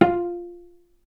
healing-soundscapes/Sound Banks/HSS_OP_Pack/Strings/cello/pizz/vc_pz-F4-ff.AIF at ae2f2fe41e2fc4dd57af0702df0fa403f34382e7
vc_pz-F4-ff.AIF